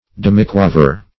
Search Result for " demiquaver" : The Collaborative International Dictionary of English v.0.48: Demiquaver \Dem"i*qua`ver\, n. (Mus.)